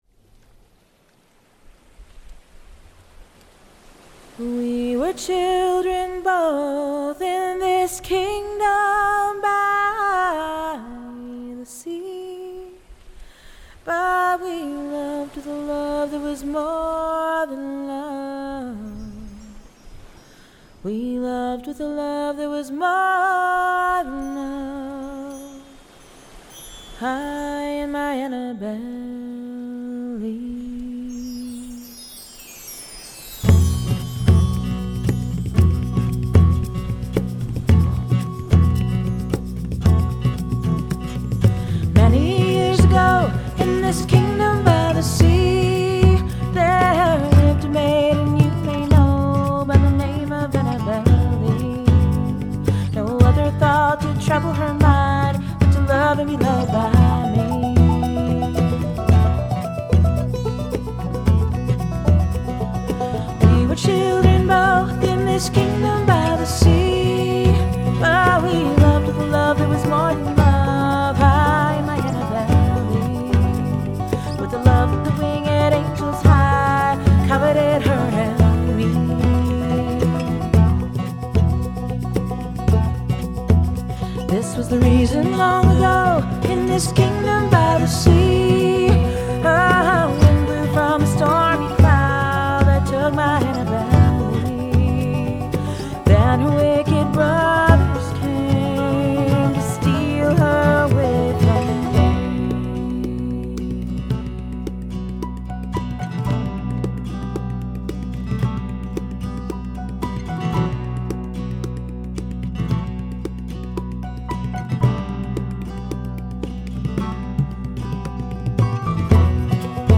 Is the shaker too loud?